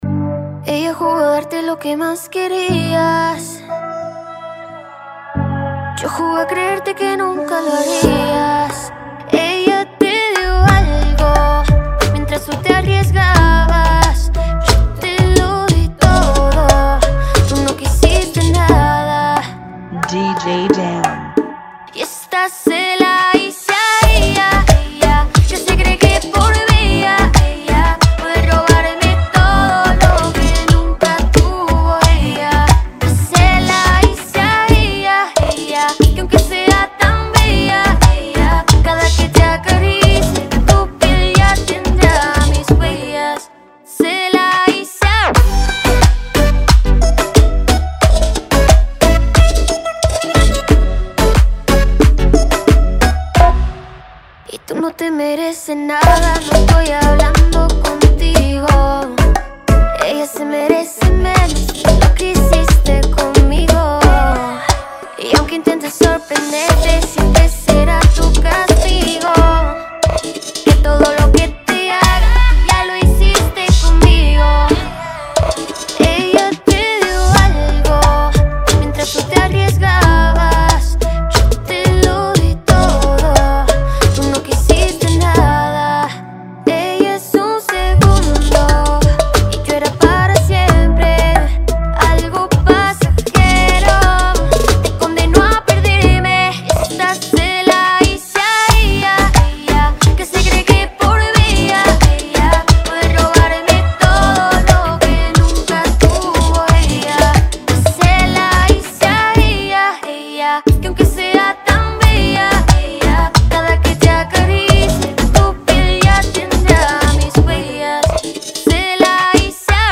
89 BPM
Genre: Bachata Remix